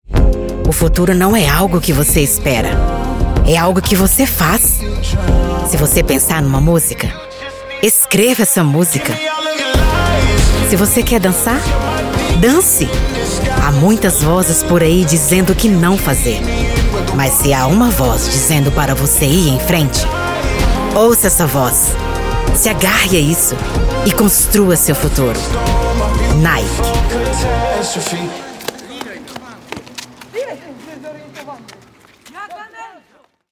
A powerful voice with good diction.
Sprechprobe: Sonstiges (Muttersprache):
I have a soft and welcoming tone of voice, but one that conveys confidence, energy and credibility.